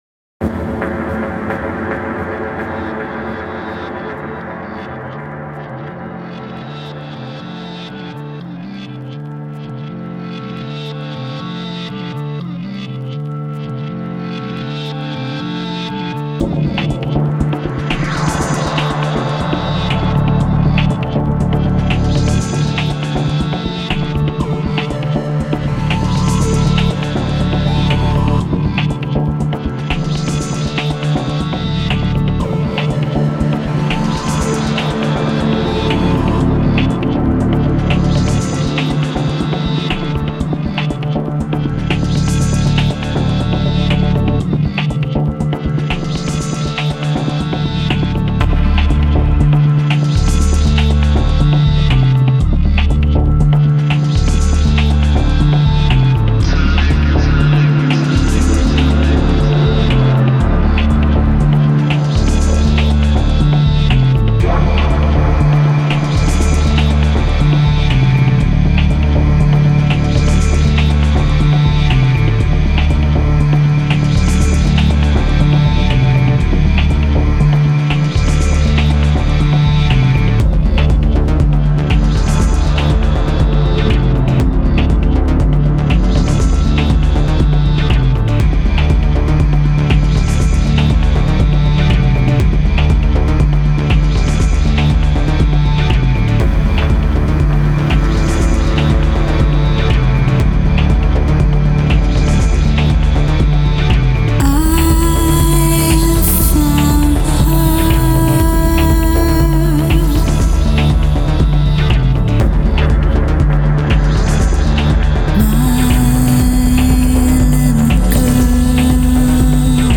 trip hop